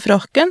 Preaspirasjon (f.eks. ) er aspirasjonsfasen som opptrer før en plosiv: "frakken" uttalt